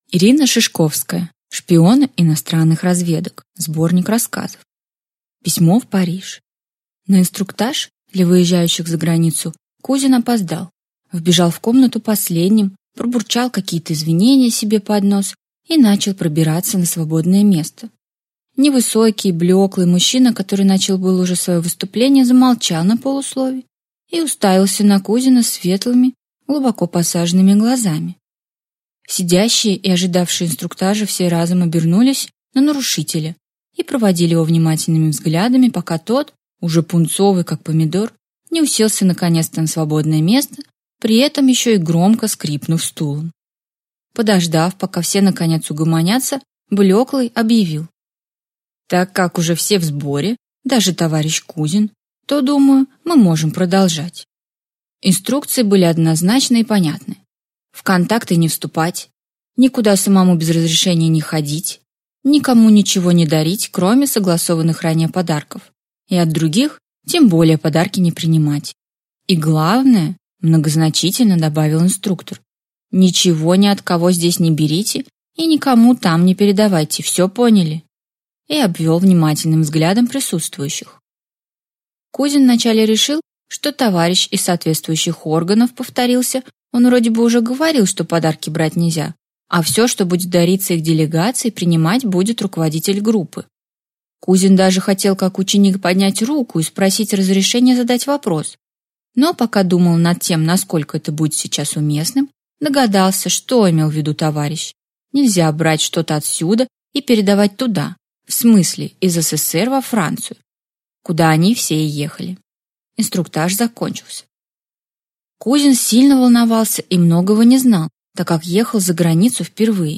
Аудиокнига Шпионы иностранных разведок. Сборник рассказов | Библиотека аудиокниг